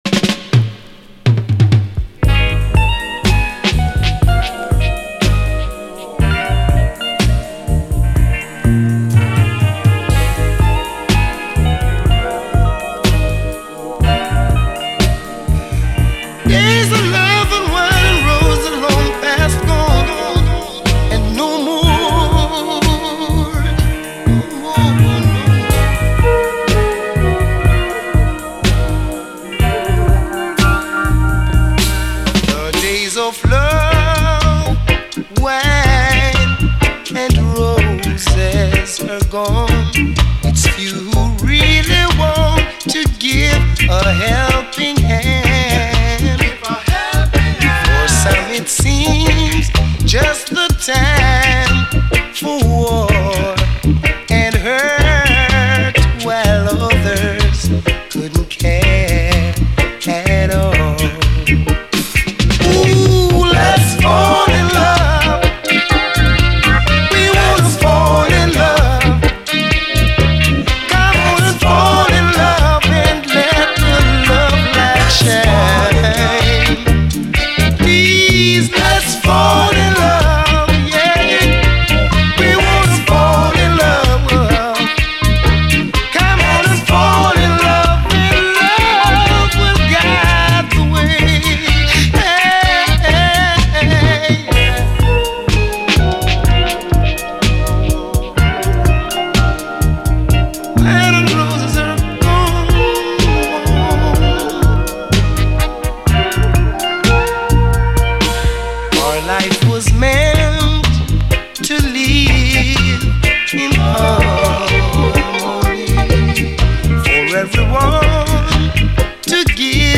REGGAE, 7INCH
ラヴァーズ・ファンにもオススメの最高スウィート・ソウルフル・レゲエ！
トロけるようなギター・プレイのメロウネス、ほとばしる哀愁感！